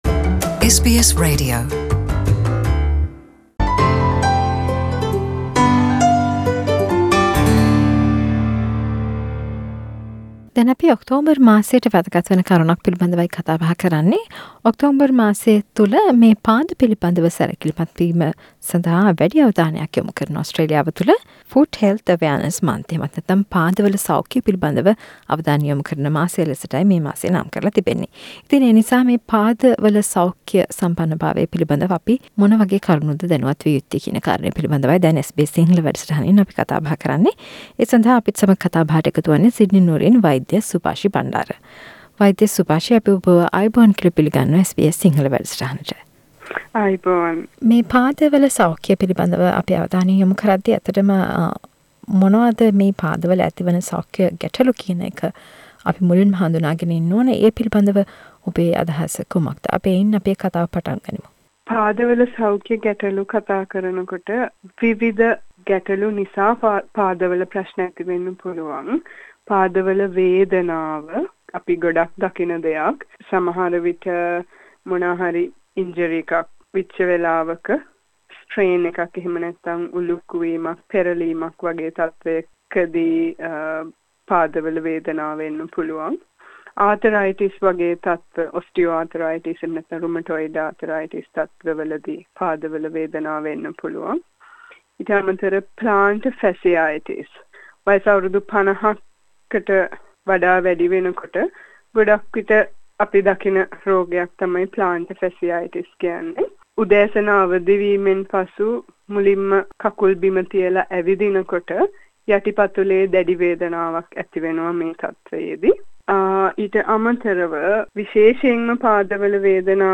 SBS සිංහල වැඩසටහන ගෙන ආ සාකච්චාව